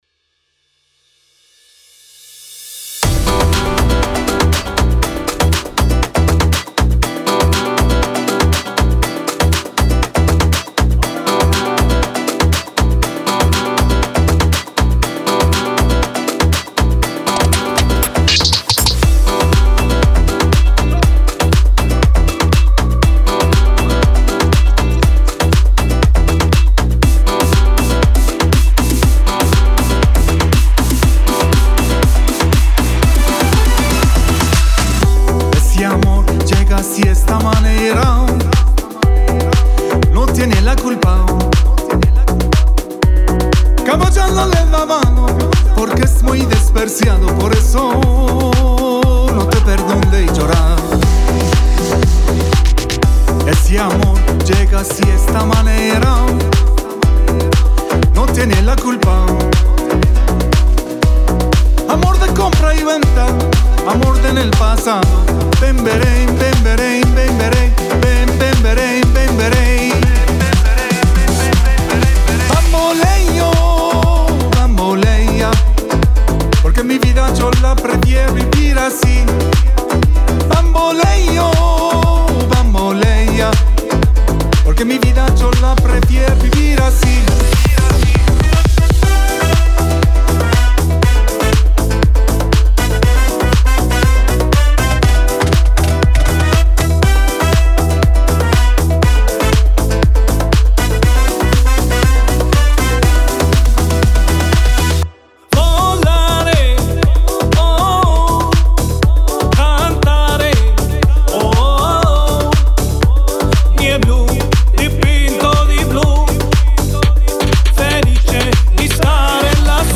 اولین موسیقی با آواز